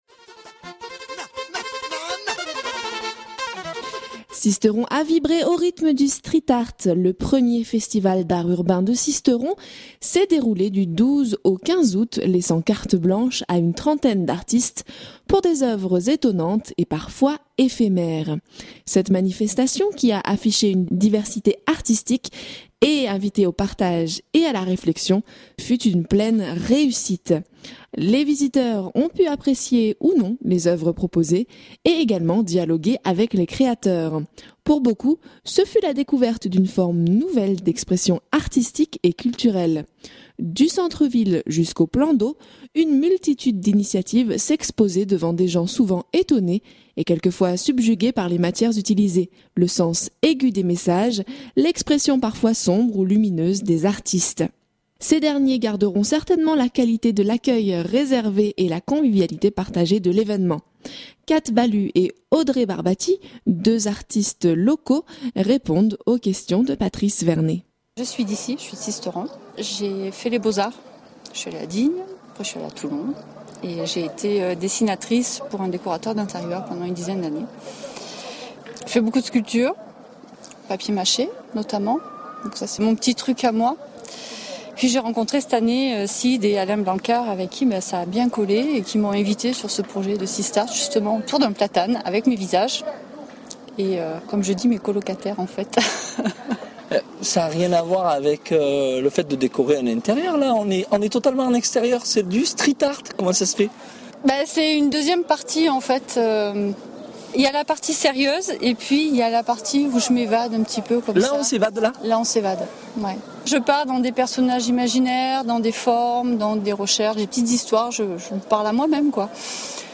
deux artistes locaux répondent aux questions